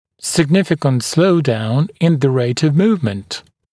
[sɪg’nɪfɪkənt ‘sləudaun ɪn ðə reɪt əv ‘muːvmənt][сиг’нификэнт ‘слоудаун ин зэ рэйт ов ‘му:вмэнт]существенное замедление темпа (скорости) перемещение